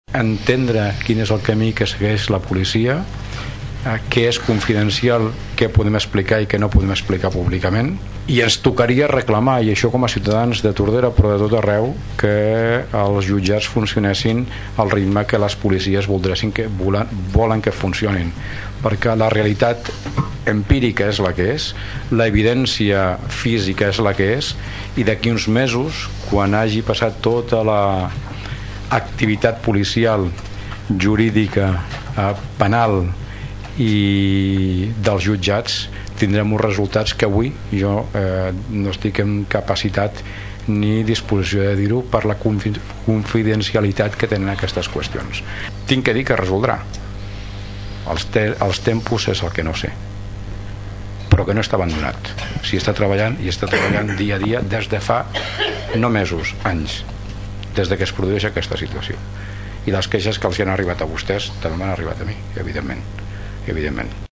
L’únic detall que han desvetllat mossos es que no es va detenir a ningú. L’alcalde de Tordera, Joan Carles Garcia demanava ahir en el plenari municipal que es deixi treballar a la policia, i confia que la justícia vagi ràpida en la resolució d’aquest tema.